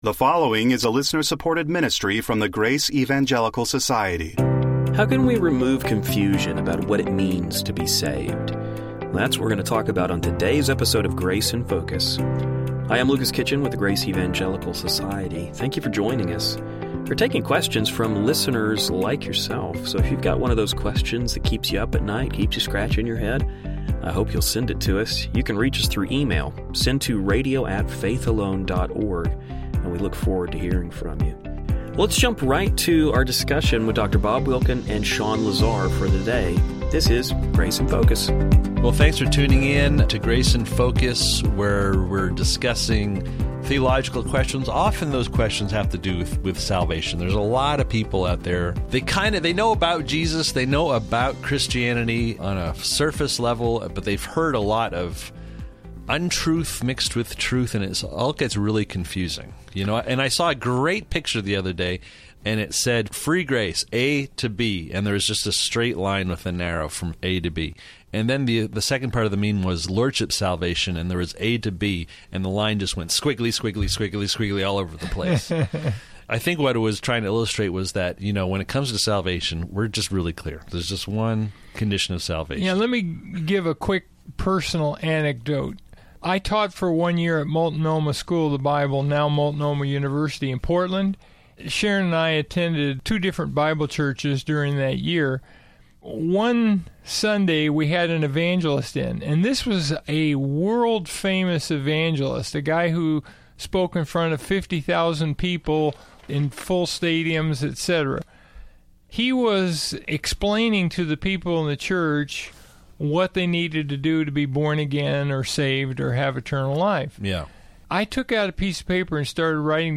Today on Grace in Focus radio, we will be answering some questions.